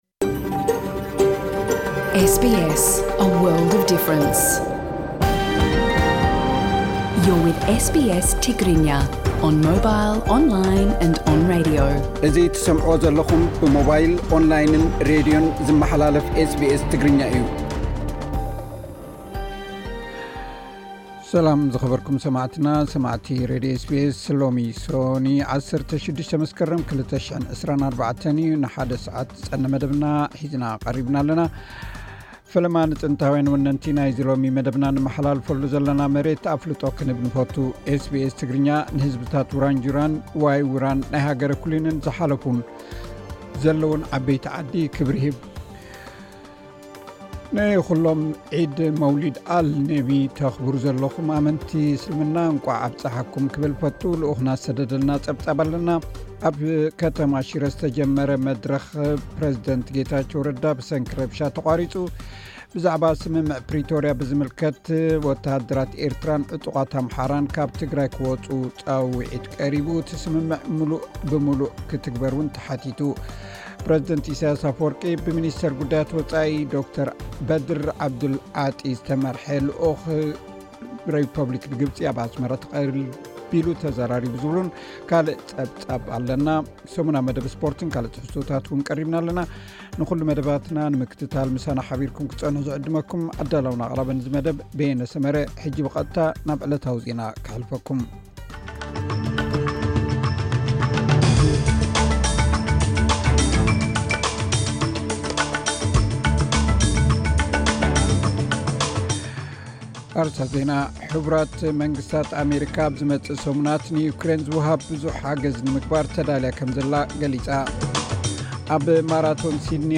ዕለታዊ ዜና ኤስ ቢ ኤስ ትግርኛ (16 መስከረም 2024)